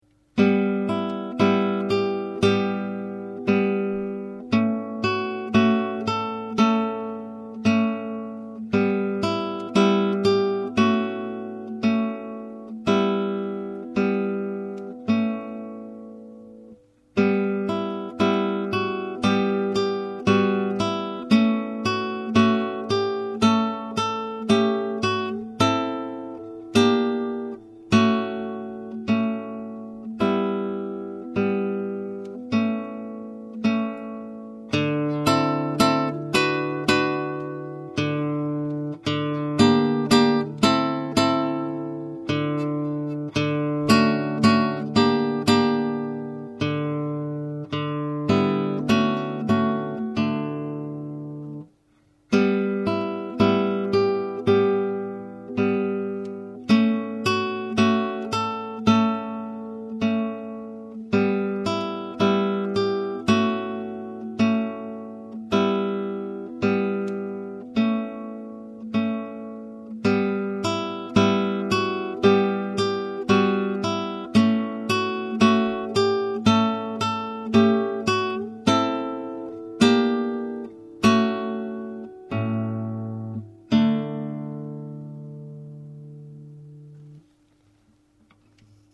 Lament Dit stukje heb ik speciaal gecomponeerd als oefening voor de barré.
Een lament is een klaaglied.